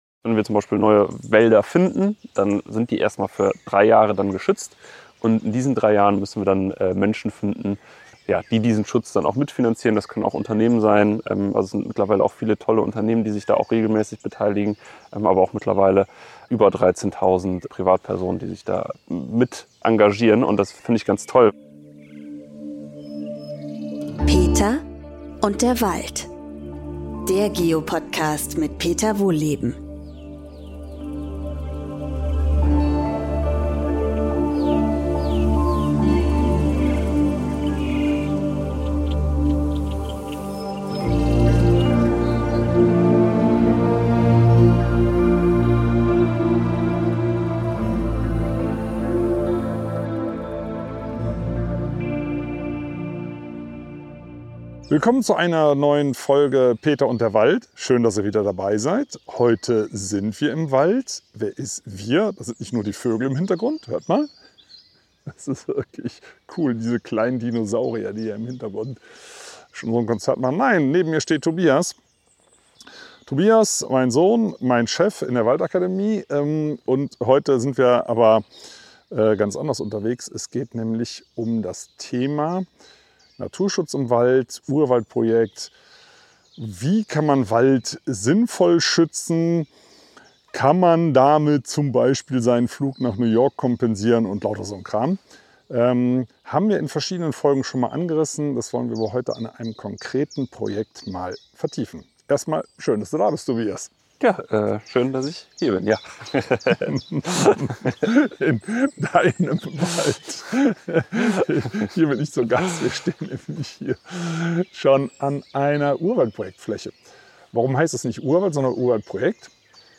Während die beiden durch den alten Buchenwald streifen, sprechen sie darüber, wie das Projekt naturbelassene Waldstücke schützt und warum das eine Win-Win-Situation für Waldbesitzende und Kommunen schafft. Außerdem gibt es im Wald Vogelgesänge und ein spannendes Forschungsprojekt zu entdecken.